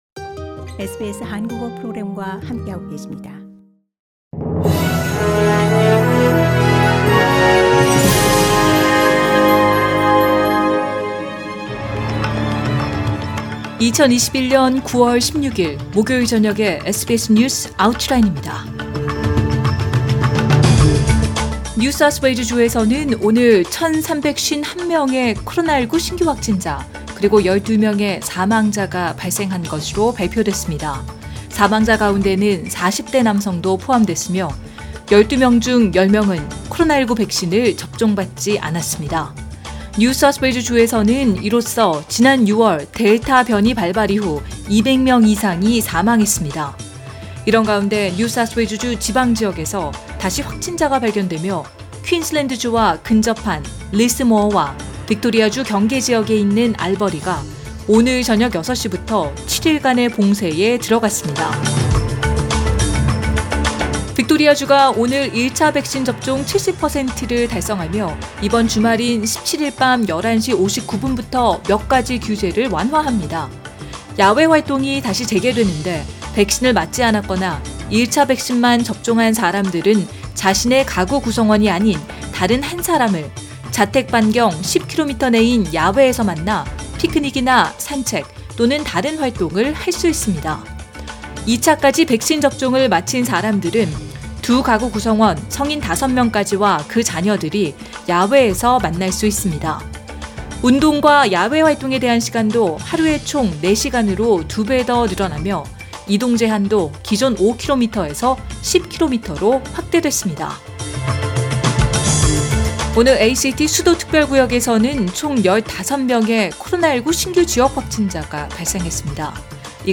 2021년 9월 16일 목요일 저녁의 SBS 뉴스 아우트라인입니다.